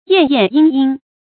燕燕莺莺 yàn yàn yīng yīng
燕燕莺莺发音